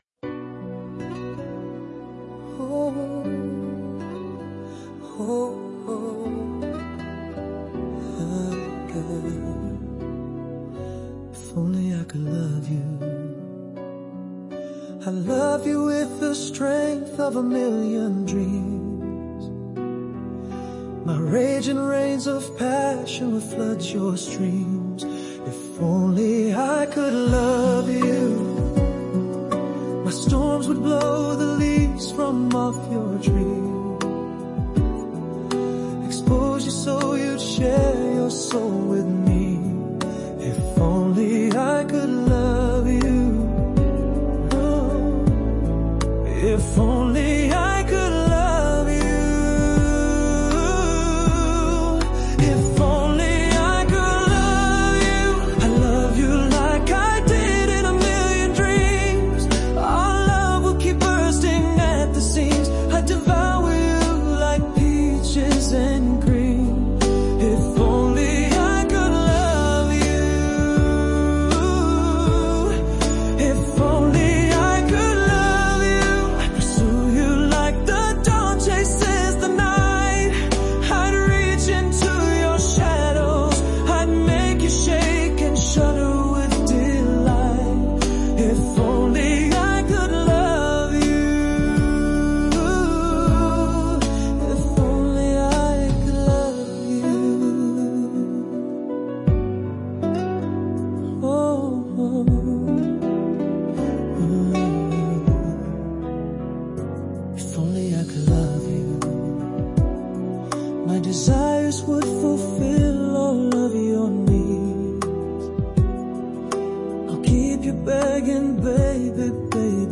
Oooo this gives me the feels.... lovely, Seductive, melancholy, and passionate!